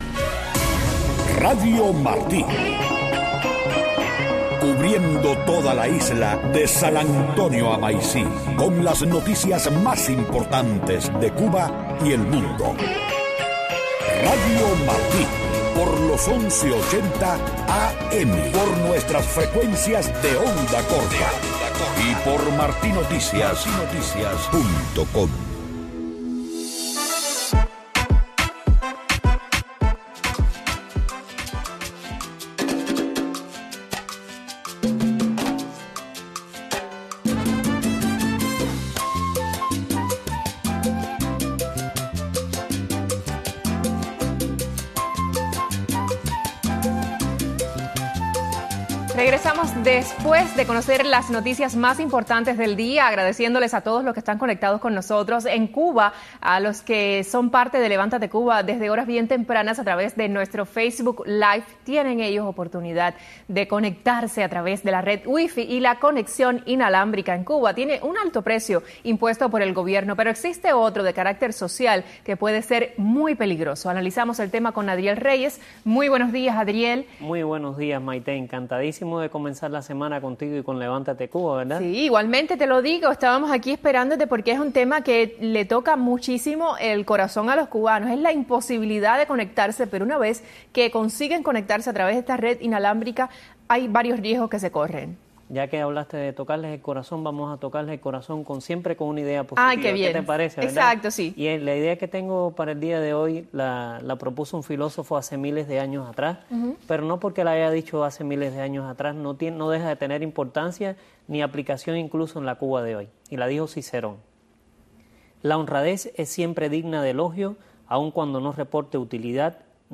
El programa cuenta con la participación de invitados habituales, analistas y periodistas especializados en temas cubanos, además de segmentos de noticias, opinión, análisis, historia, economía, temas sociales, tradiciones, arte, cultura, tecnología y tópicos que impactan la vida de los cubanos de la isla.